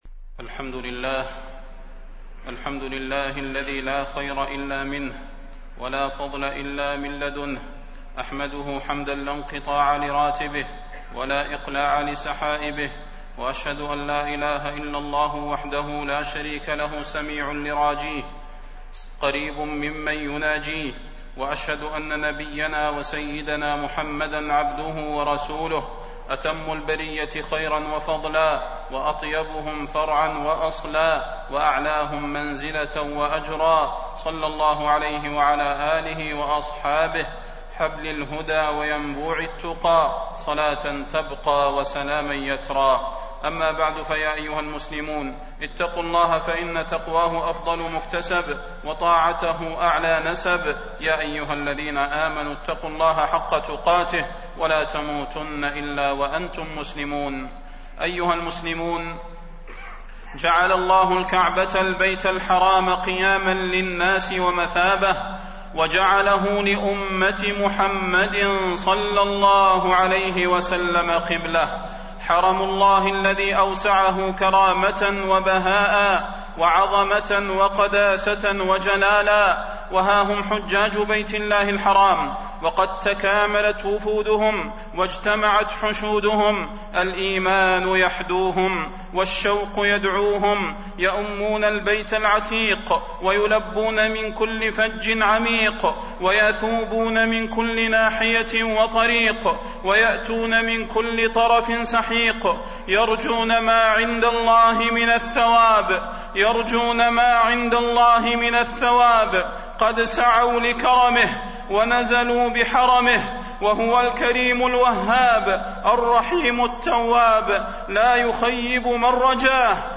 تاريخ النشر ٦ ذو الحجة ١٤٣١ هـ المكان: المسجد النبوي الشيخ: فضيلة الشيخ د. صلاح بن محمد البدير فضيلة الشيخ د. صلاح بن محمد البدير نصائح للحجاج